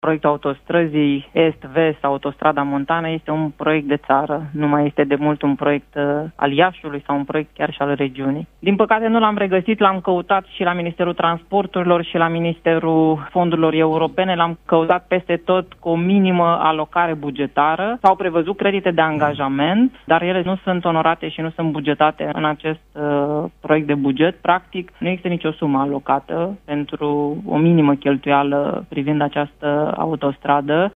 Vicepreședintele Senatului, Iulia Scântei, parlamentar liberal de Iași, a studiat documentul și a declarat, în emisiunea Imperativ, de la postul nostru de radio, că acesta este un proiect de țară și nu unul de interes doar local sau regional: